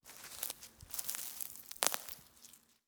SFX_Harvesting_03_Reverb.wav